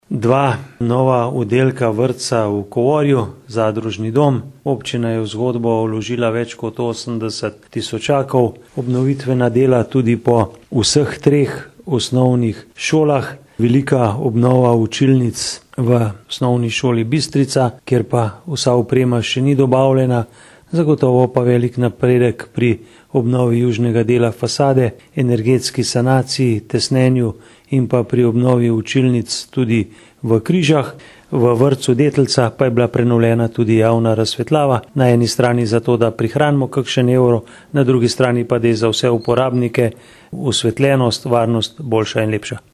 izjava_mag.borutsajoviczupanobcinetrzic_sole.mp3 (1,0MB)